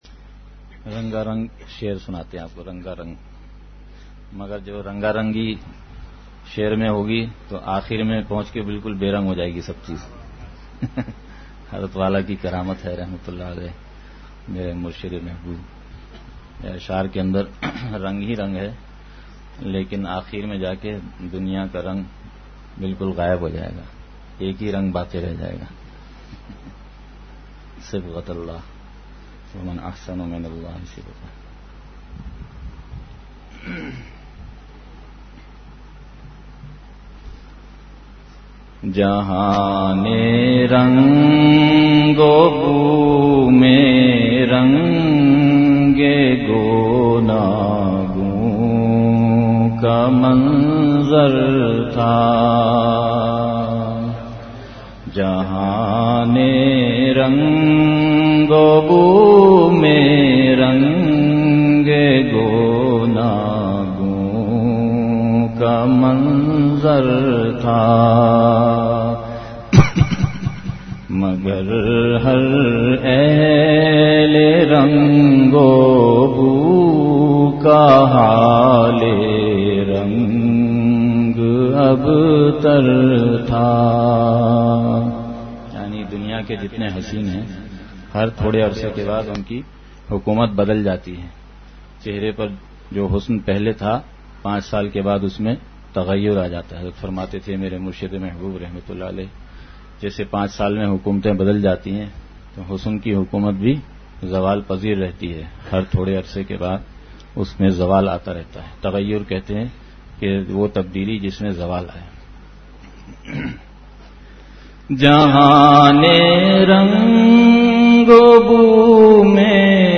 مجلس کے پہلے حصے کے اختتام پر درد بھرے دُعا بھی فرمائی۔